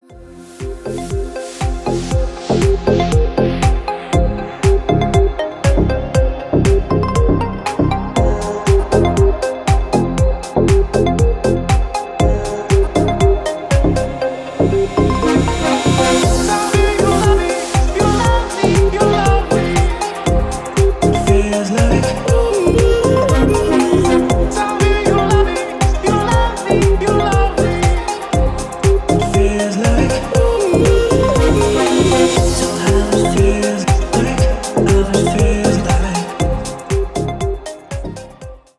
мужской голос
deep house
Electronic
спокойные
Стиль: deep house.